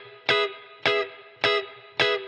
DD_TeleChop_105-Emaj.wav